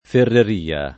ferreria [ ferrer & a ]